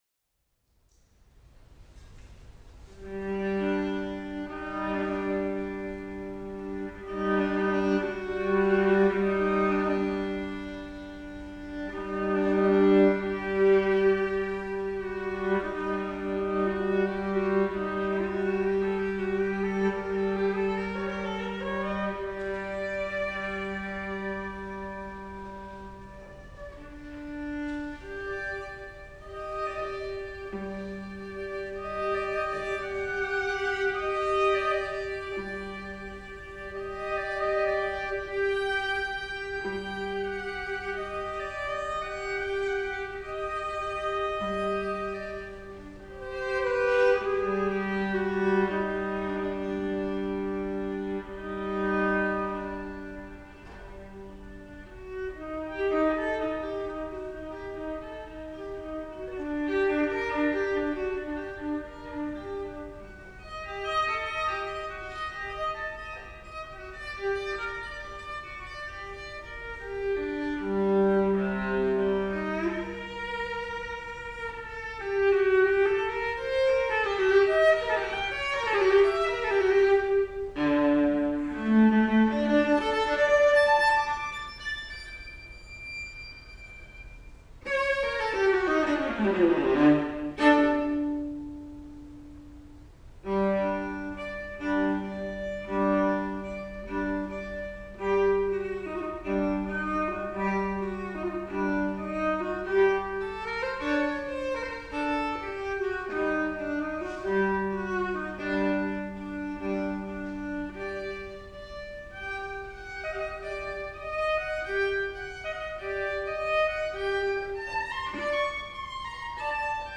Except as noted, you will hear the composer playing viola,
for solo viola $5.00
inspired by the colorful scales and hypnotic rhythms
of music associated with Armenia; positions 1-10;